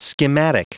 Prononciation du mot schematic en anglais (fichier audio)
Prononciation du mot : schematic